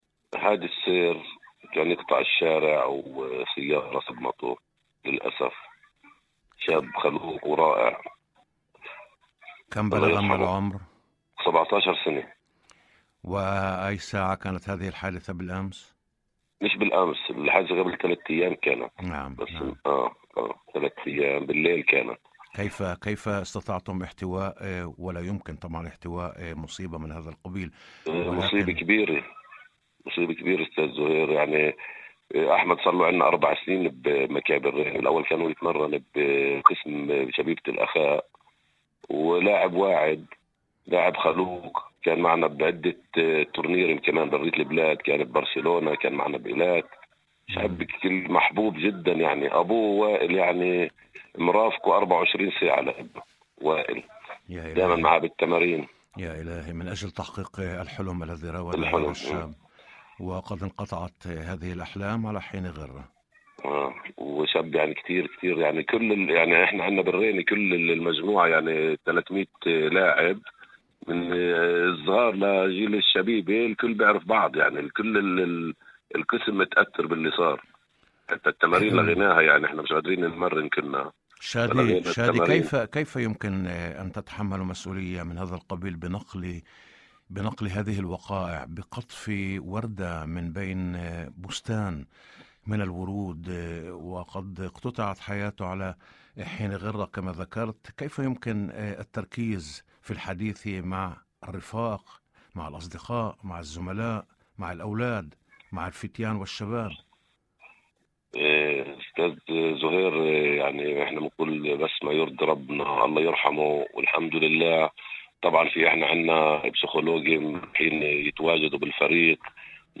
وأضاف في مداخلة هاتفية برنامج "يوم جديد"، على إذاعة الشمس: